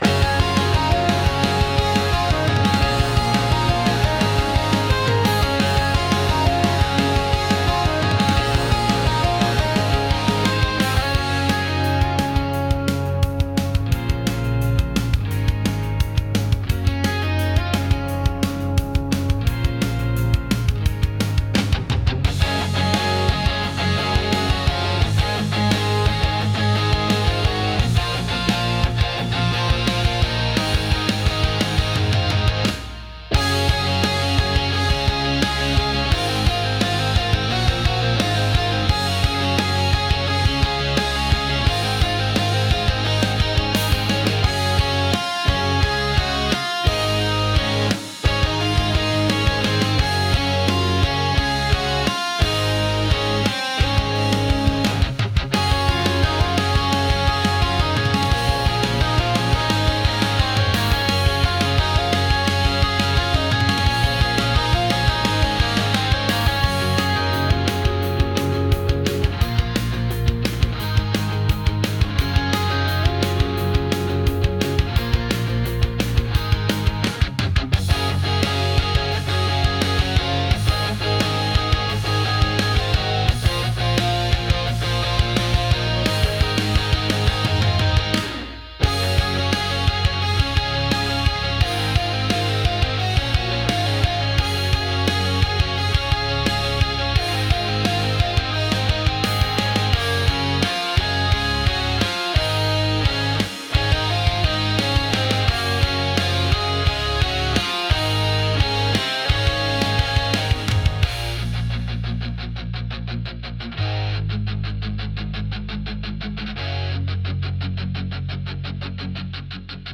Genre: Pop Punk Mood: High Energy Editor's Choice